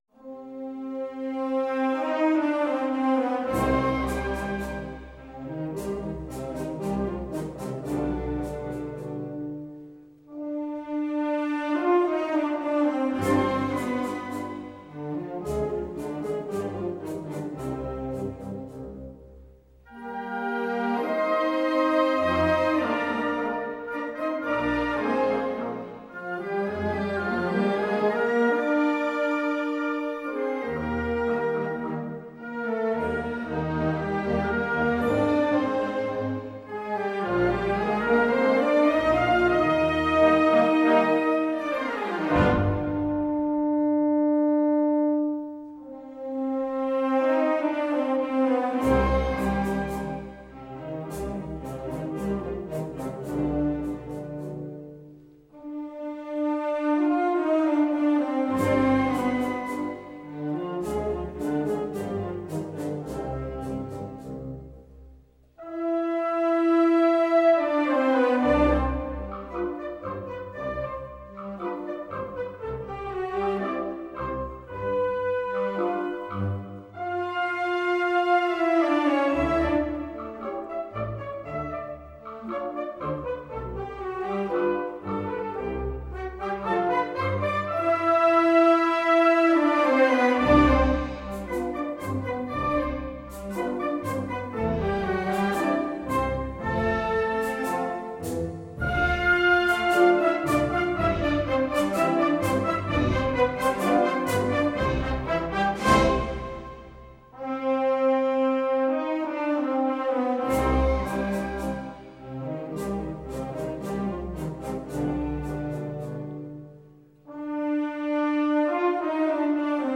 Tanto milonga